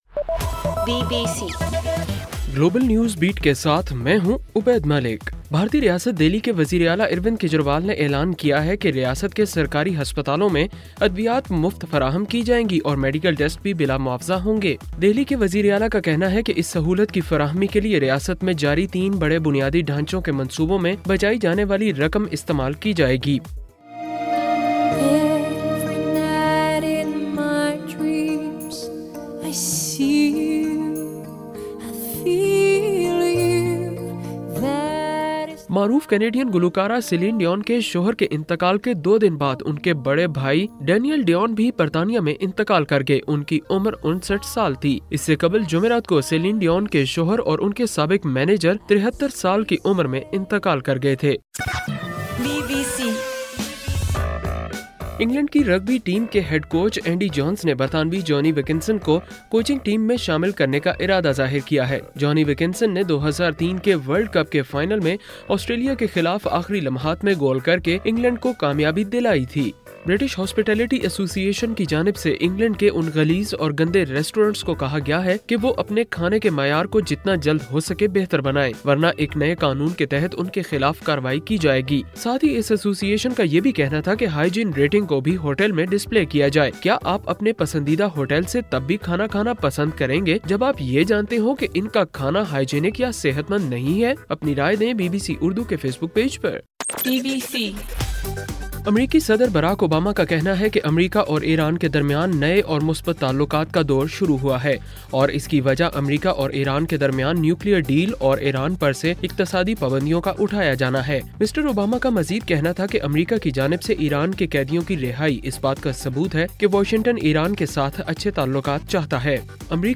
جنوری 17: رات 11 بجے کا گلوبل نیوز بیٹ بُلیٹن